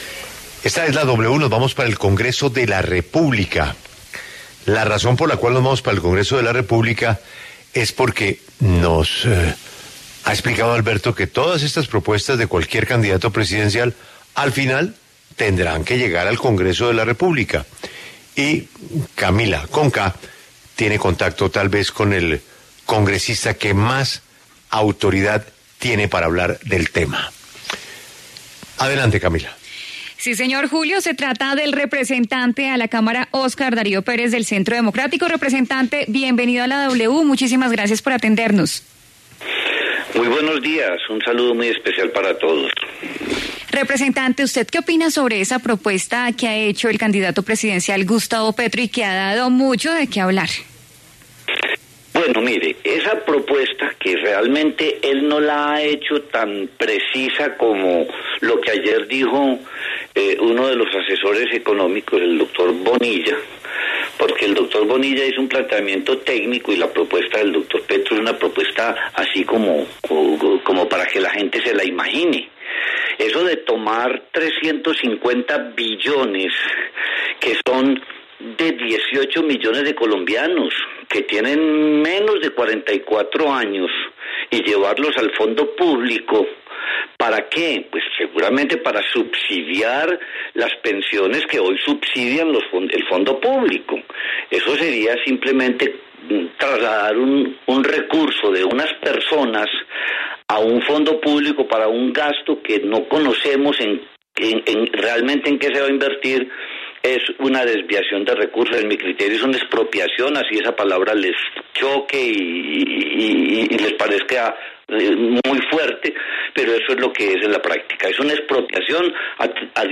En diálogo con la W, el representante Óscar Darío Pérez del Centro Democrático se refirió a la propuesta del candidato presidencial Gustavo Petro en el tema pensional de nacionalizar el ahorro de las personas para que sean tomadas por el fondo público (Colpensiones).